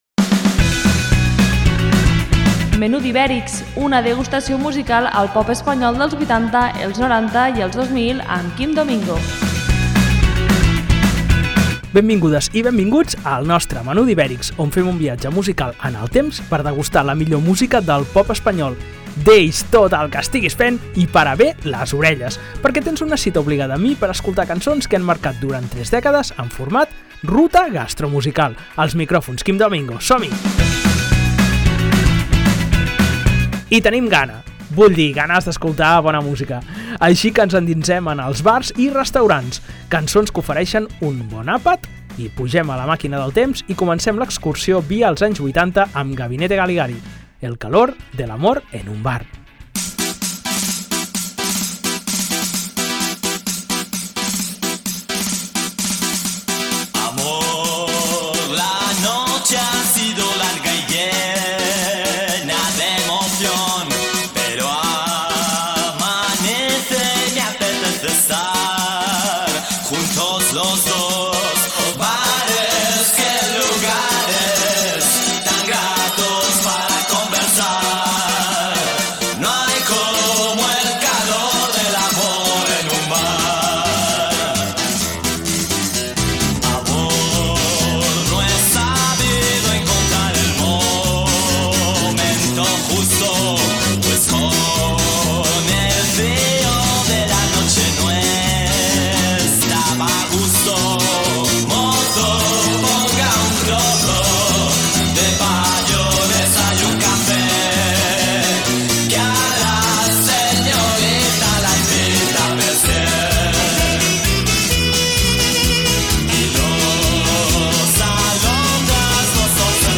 En esta ocasió, escoltem cançons que expliquen històries de bars.